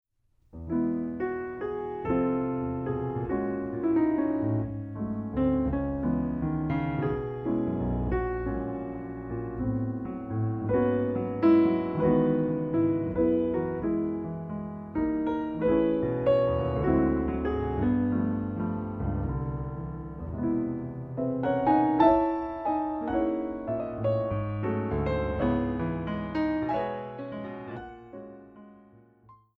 Solo Piano Concert
Recording: Ralston Hall, Santa Barbara, CA, January, 2008
Piano
Soundclip:  The opening statement of the melody